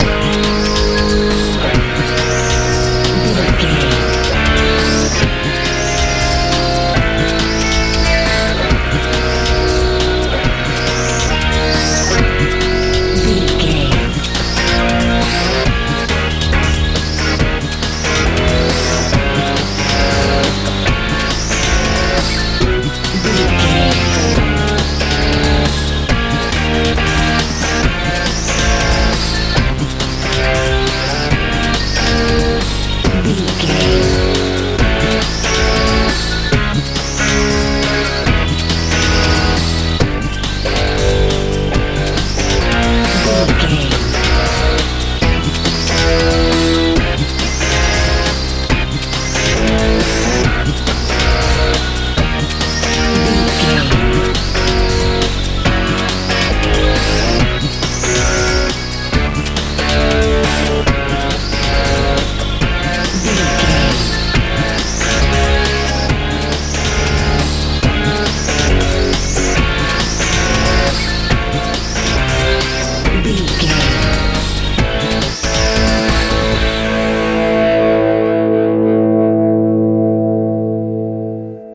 dance feel
Ionian/Major
A♭
strange
energetic
electric guitar
synthesiser
bass guitar
drums
80s
90s
suspense
bright